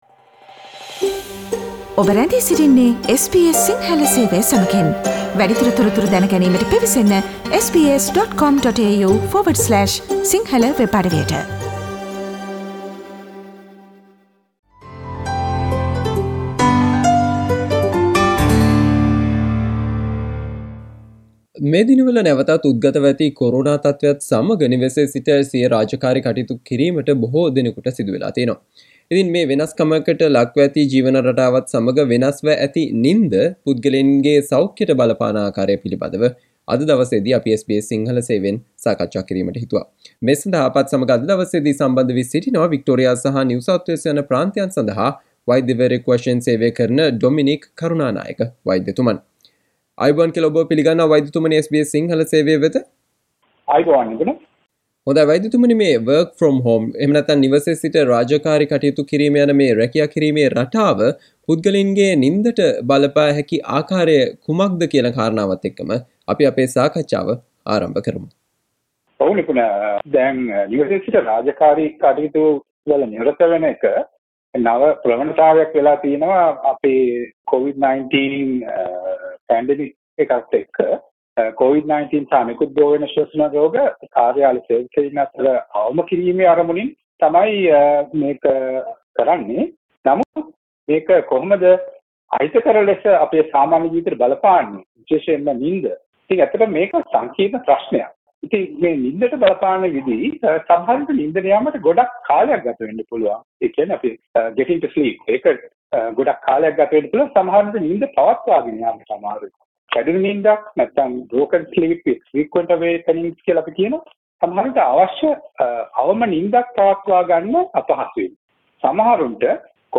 ඕස්ට්‍රේලියාව තුල නැවතත් උද්ගතව ඇති කොරෝනා තත්වය හේතුවෙන් නිවසේ සිට රාජකාරි කටයුතු කිරීමේදී වෙනස්කමකට ලක්ව ජීවන රටාවත් සමග වෙනස්ව ඇති නින්ද පුද්ගලයින්ගේ සෞඛ්‍යයට බලපාන ආකාරය සම්බන්ධයෙන් SBS සිංහල සේවය සිදු කල සාකච්චාවට ඔබට මේ ඔස්සේ සවන් දිය හැක.